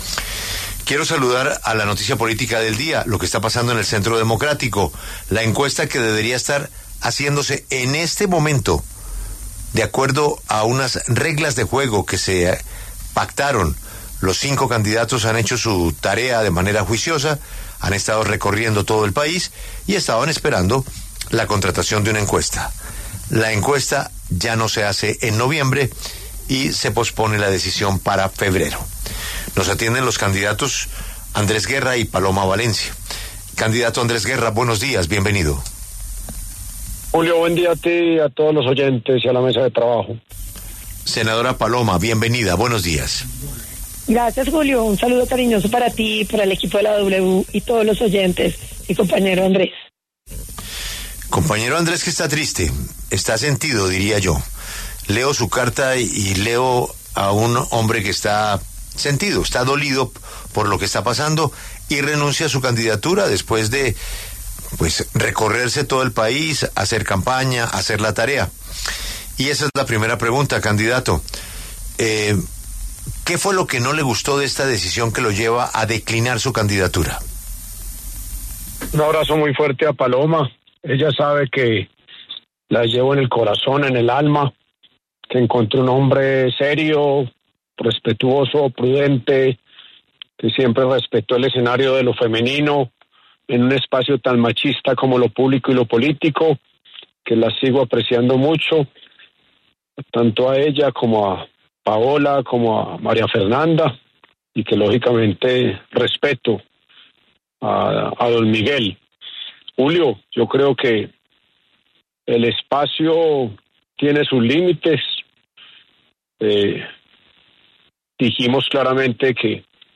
El senador Andrés Guerra explicó en los micrófonos de La W los motivos de la renuncia a su aspiración. Su colega, Paloma Valencia, habló de lo que viene en el Centro Democrático tras la crisis interna.